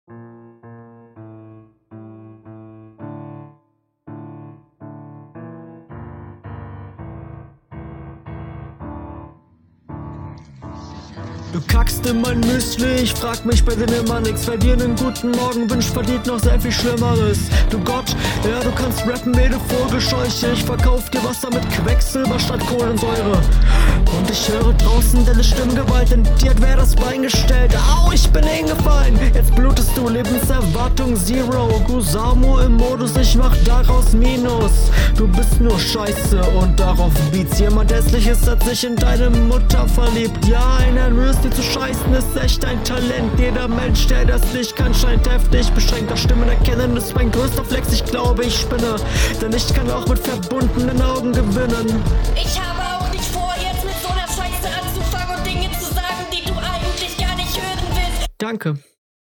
Soundtechnisch nicht so geil tbh aber dafür im ganzen Rest bisschen mehr dabei.